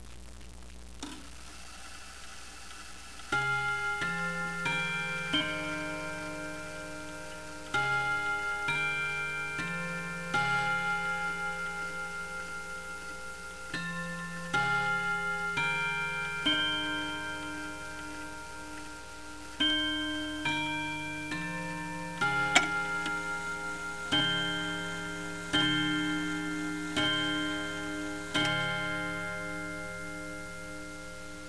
Seth Thomas Sonora Clock
This is a Seth Thomas Chime Clock #57, made in 1921, and it originally sold at that time for $75.00. Sonora clocks use a normal American time and strike movement, and a separate chiming movement.
Sonora clocks are usually on bells, but also on rods (like this one).